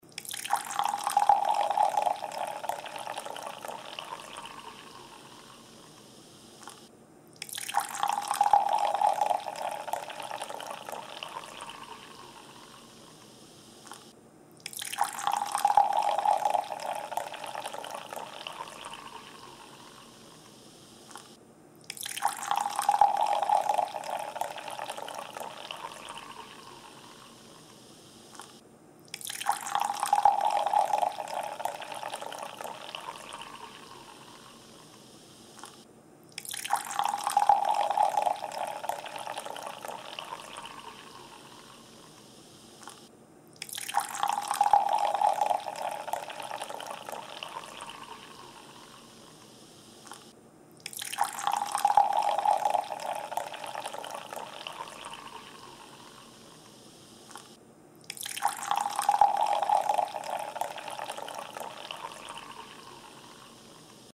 Звук воды в стакан, чтобы писать
• Категория: Звуки воды, чтобы пописать
• Качество: Высокое